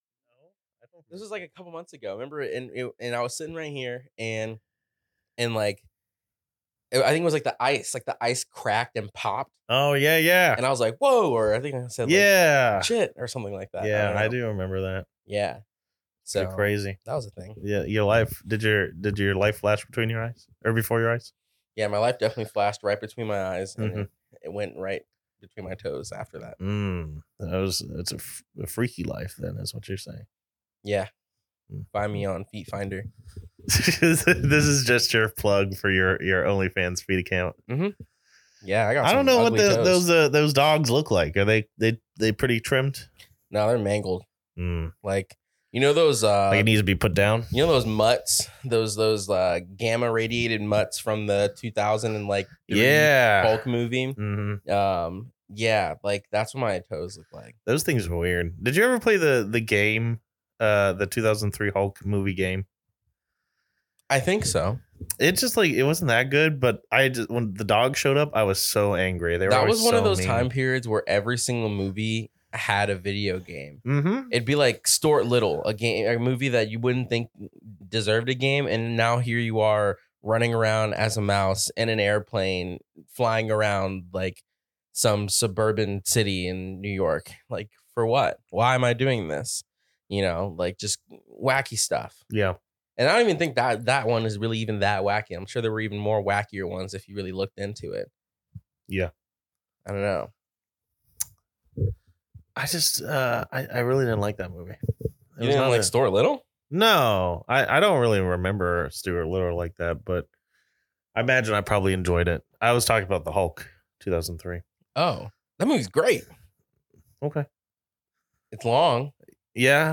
Two friends, in a room, with reel thoughts about films that came from our heads.